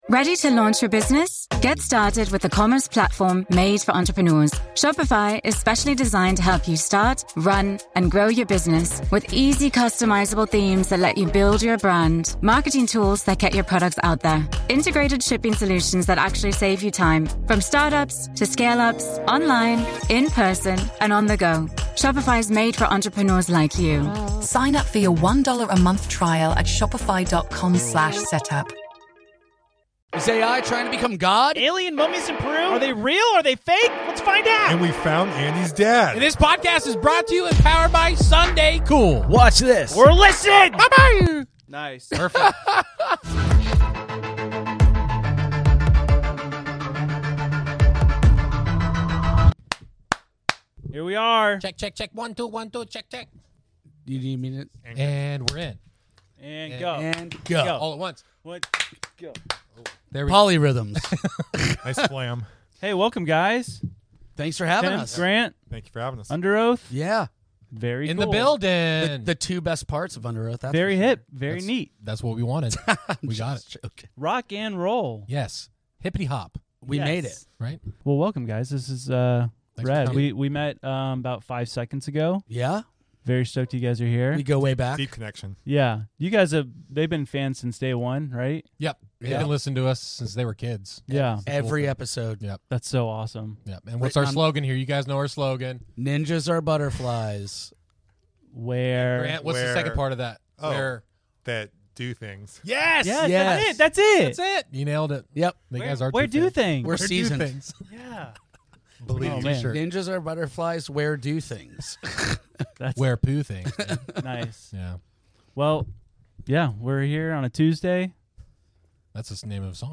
We dig into the mysterious three-fingered Peruvian alien mummies—are they ancient extraterrestrials, elaborate hoaxes, or something even weirder? Then we shift gears and talk about the rapid rise of artificial intelligence, how it’s already reshaping our world, and what it means for the future of humanity, creativity, and even the music industry. And as always, there are plenty of laughs, wild theories, and moments that make you question reality.